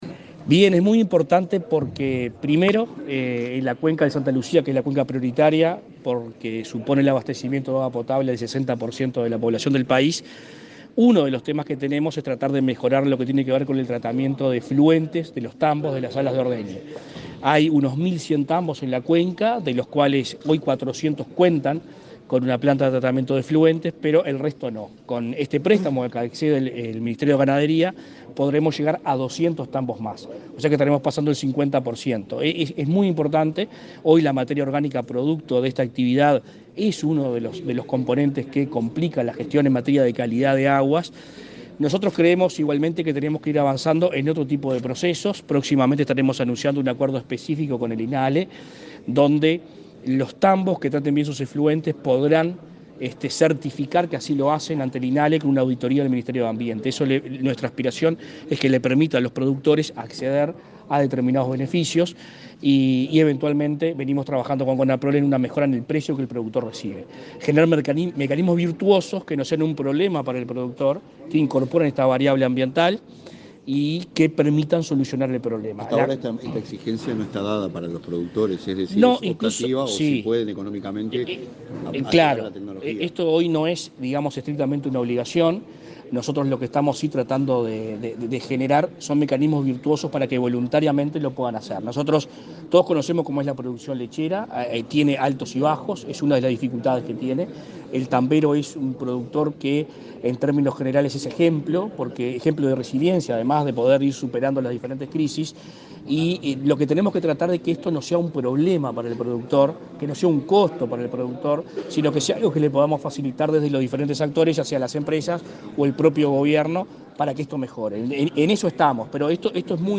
Declaraciones del ministro de Ambiente, Adrián Peña
Declaraciones del ministro de Ambiente, Adrián Peña 05/09/2022 Compartir Facebook X Copiar enlace WhatsApp LinkedIn El ministro de Ambiente, Adrián Peña, y su par de Ganadería, Fernando Mattos, asistieron al lanzamiento del llamado a productores lecheros para participar en el proyecto de solución a la gestión de efluentes de la cuenca del Santa Lucía. Luego, Peña dialogó con la prensa.